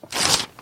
撕扯 撕扯纸张
描述：撕裂撕纸